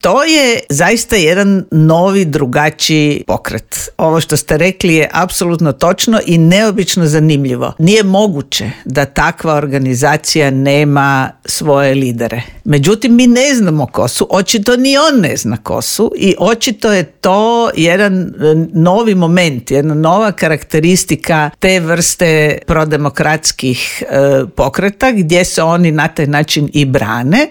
U Intervjuu Media servisa ugostili smo bivšu ministricu vanjskih i europskih poslova Vesnu Pusić koja kaže da je civilno društvo u Srbiji pokazalo da tamo postoji demokratska javnost što se poklopilo sa zamorom materijala vlasti: